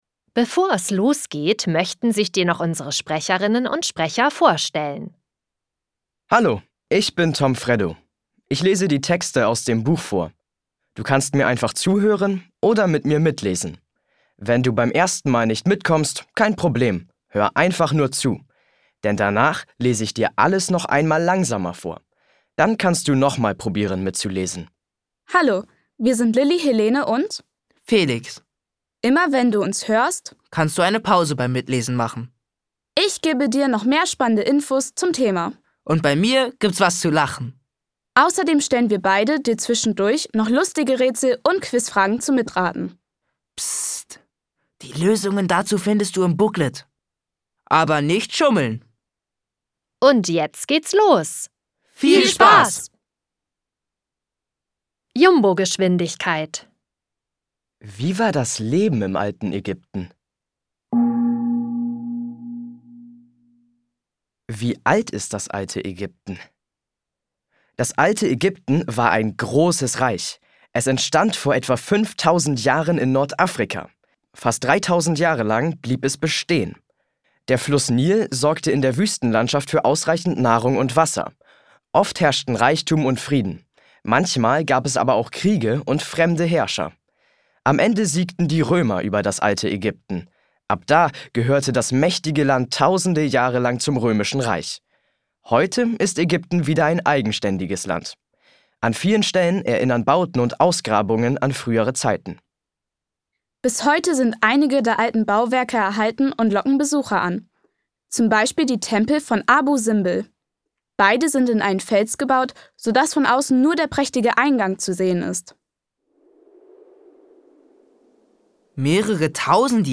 Hörbuch: Wieso?